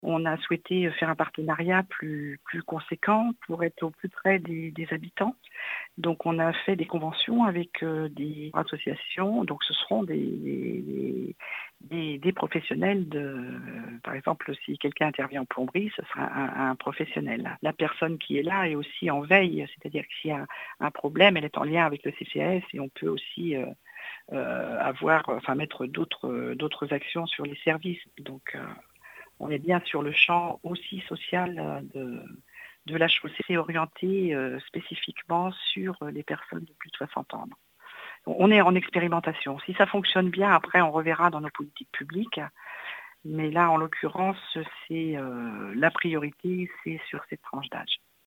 Catherine Piau, adjointe à la ville de Nantes en charge des seniors, des personnes âgées et de la Solidarité Intergénérationnelle, développe la portée sociale du dispositif.